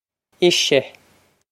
ee-sha
This is an approximate phonetic pronunciation of the phrase.